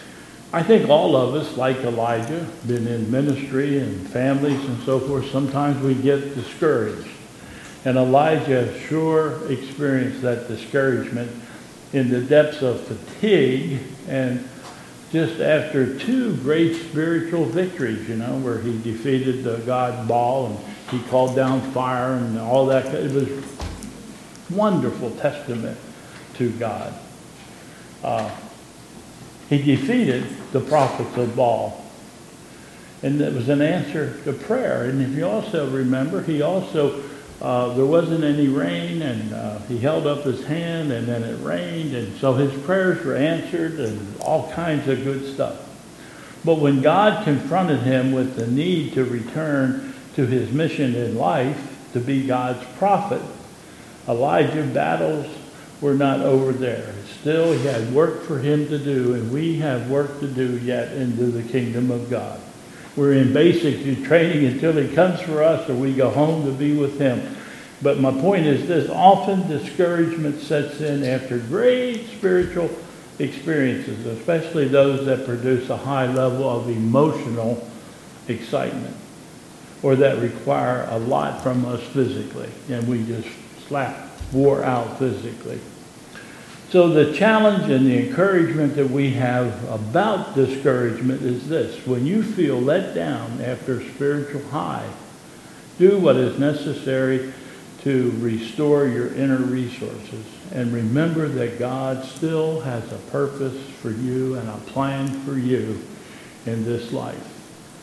OT Scripture - 1 Kings 19:1-4